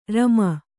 ♪ rama